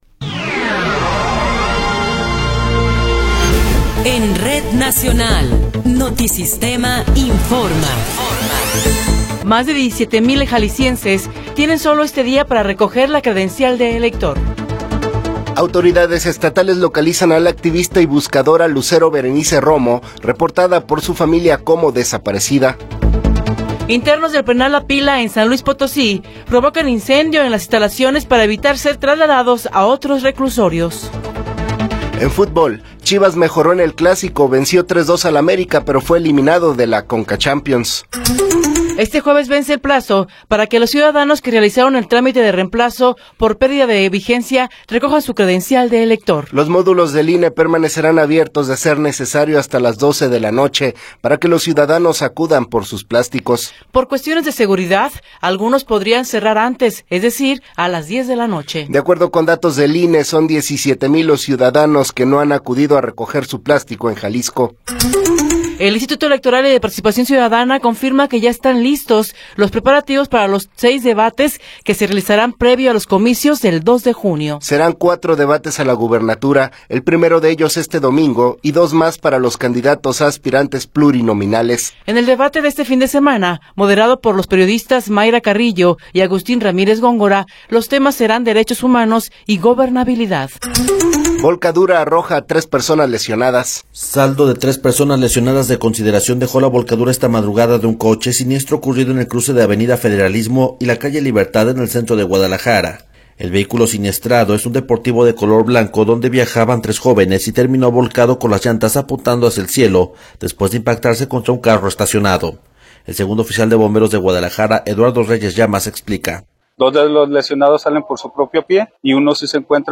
Noticiero 9 hrs. – 14 de Marzo de 2024
Resumen informativo Notisistema, la mejor y más completa información cada hora en la hora.